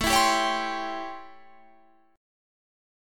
A7#9 chord